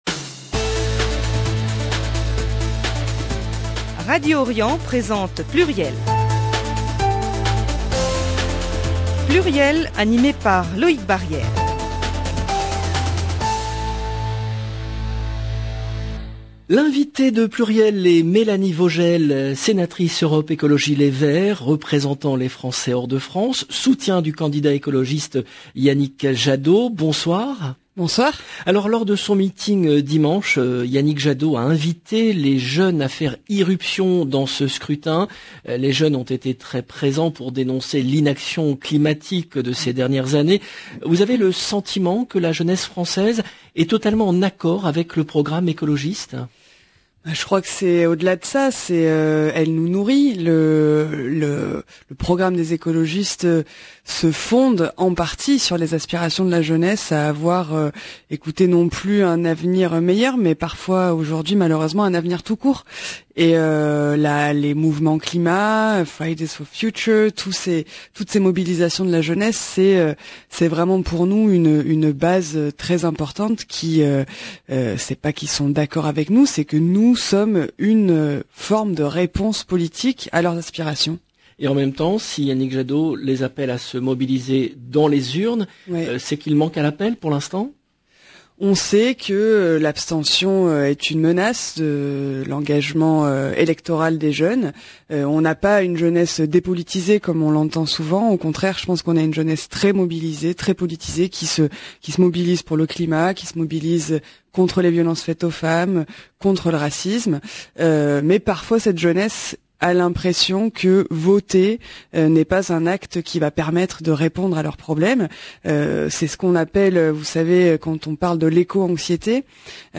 Mélanie Vogel, sénatrice EELV, soutien de Yannick Jadot.
le rendez-vous politique du mardi 29 mars 2022 L’invitée de Pluriel est Mélanie Vogel, sénatrice EELV représentant les Français hors de France, soutien du candidat écologiste Yannick Jadot .